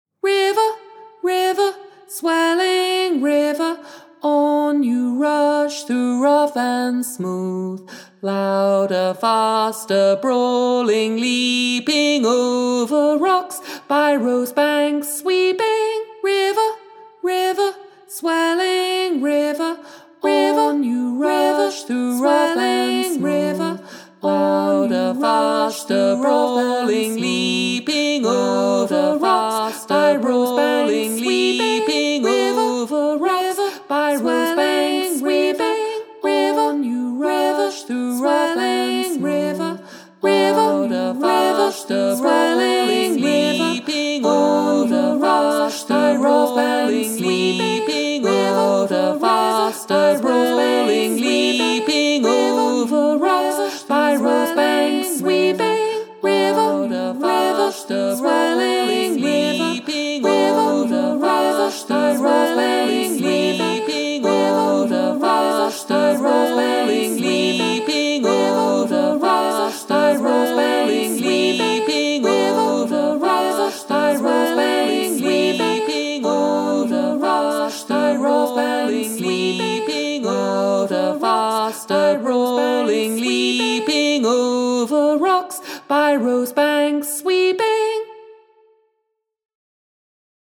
Rounds and Canons